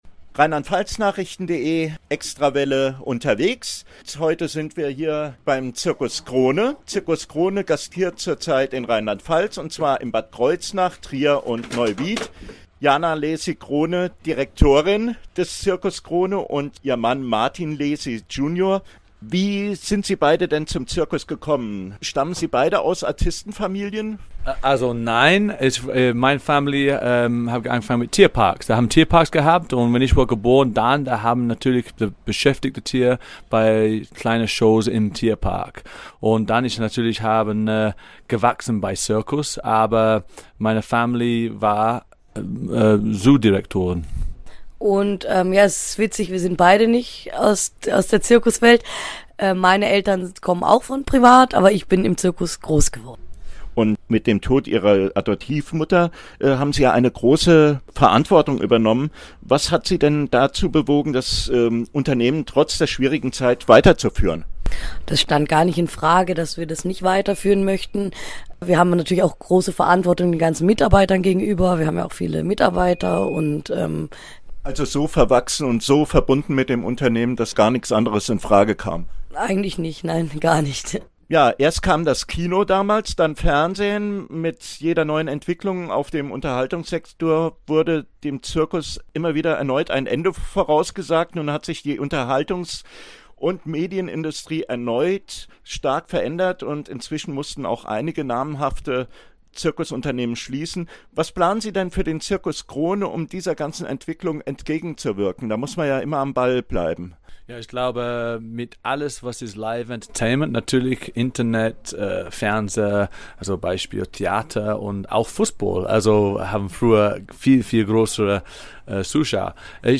Audio-Interview über die Zirkuswelt, Tiere und Artisten